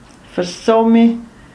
[vəʁˈsɔmi]/?) isch es Dorf i dr politische Gmeind Safietaal im Kanton Graubünde.
Roh-sursilvan-Versomi.ogg.mp3